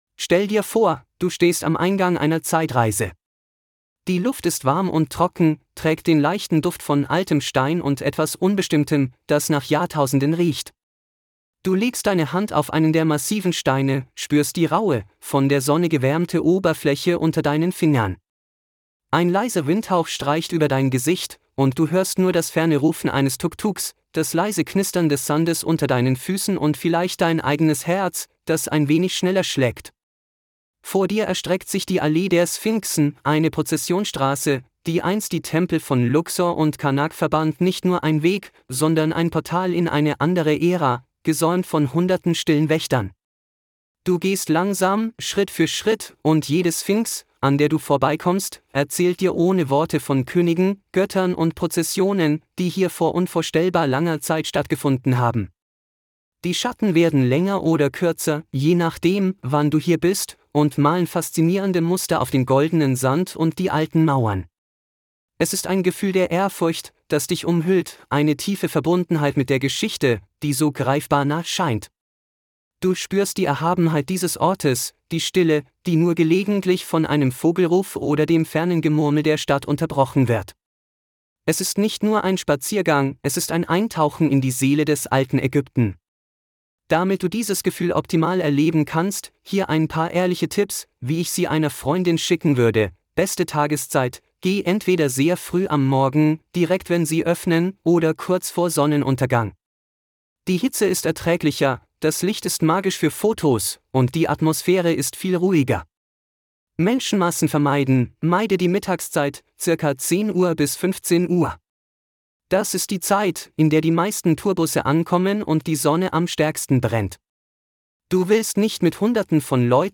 🎧 Verfügbare Audioguides (2) Guide für emotionale Erfahrungen (DE) 🔗 In neuem Tab öffnen Praktische Informationen (DE) 🔗 In neuem Tab öffnen